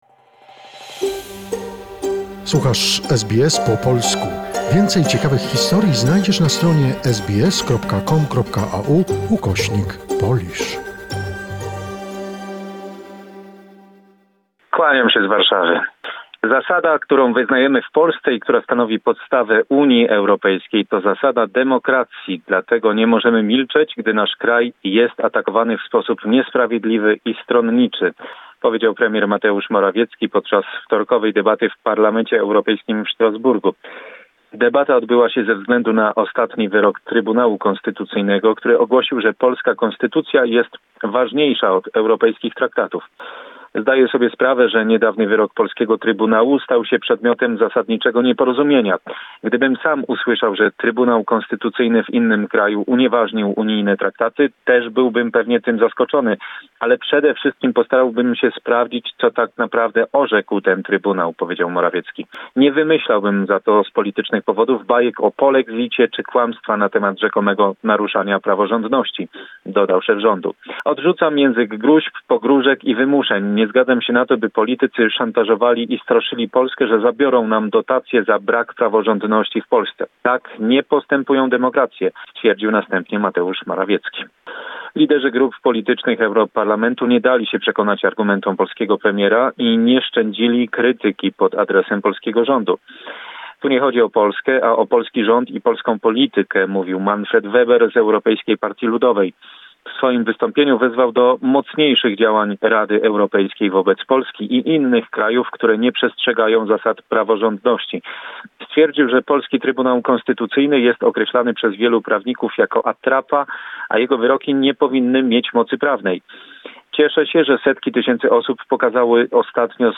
Summary of the most important events of last week in Poland. Report